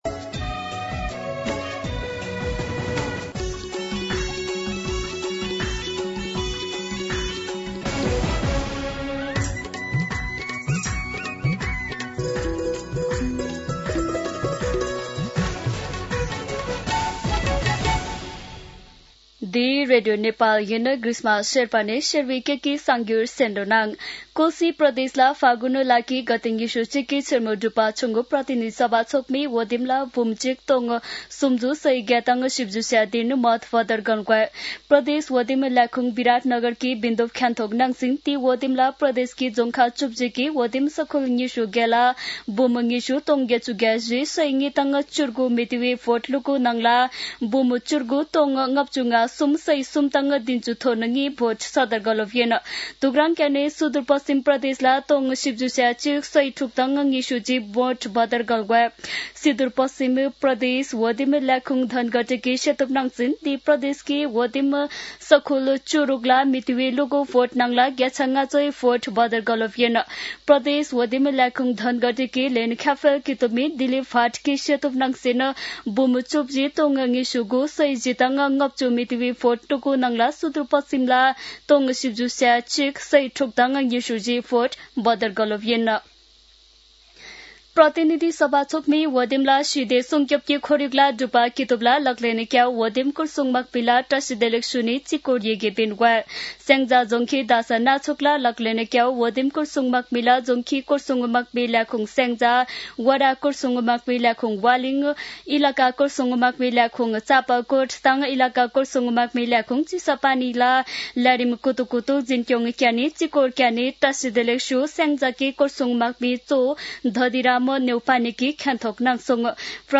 An online outlet of Nepal's national radio broadcaster
शेर्पा भाषाको समाचार : २८ फागुन , २०८२